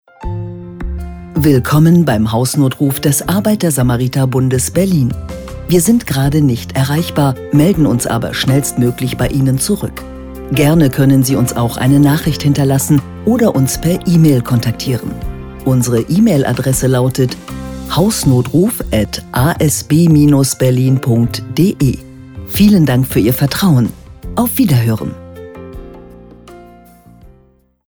Telefonansage für ASB Hausnotruf
Telefonansagen mit echten Stimmen – keine KI !!!
Die Zielsetzung war eindeutig: eine freundliche, beruhigende und gleichzeitig professionelle Bandansage, die auch in einer sensiblen Situation Sicherheit vermittelt.
Für die passende emotionale Wirkung sorgt die Musik „Good Life“, die eine positive und zugleich ruhige Atmosphäre schafft.